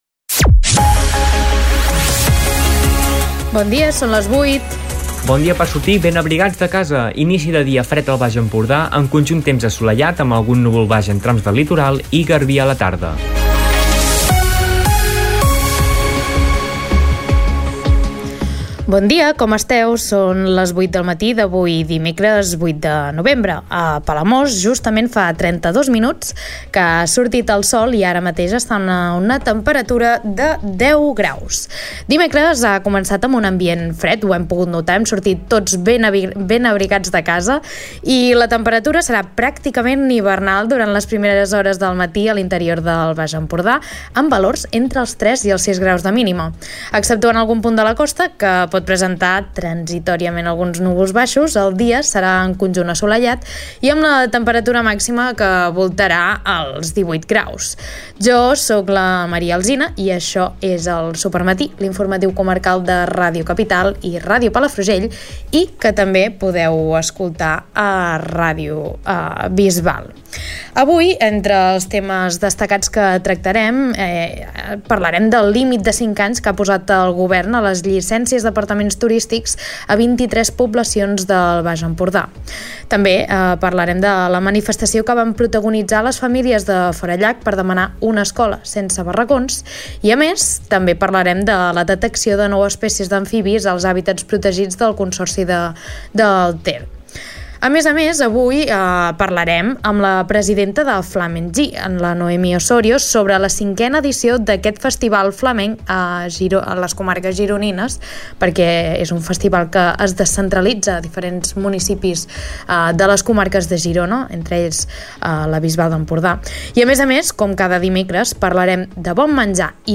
Escolta l'informatiu d'aquest dimecres